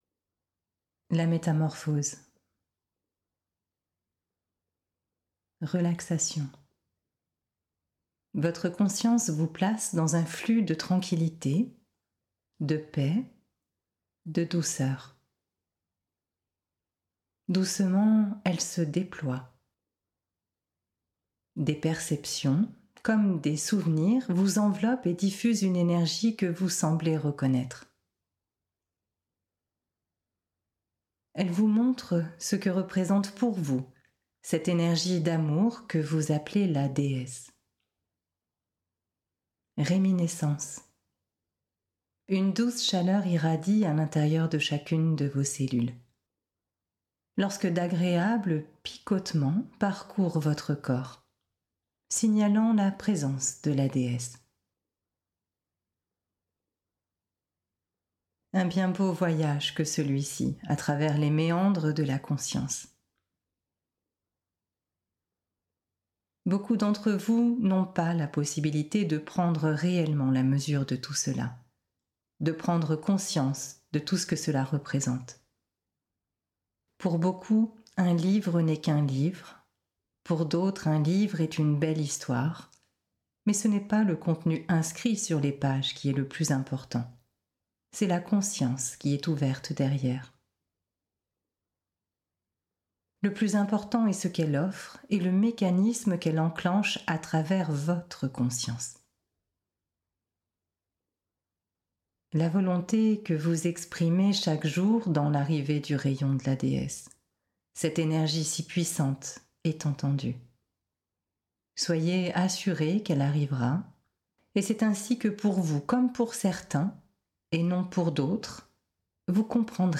Relaxation. Votre conscience vous place dans un flux de tranquillité, de paix, de douceur.